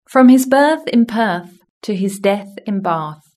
Index of /platform/shared/global-exercises/pron-tool/british-english/sound/sentences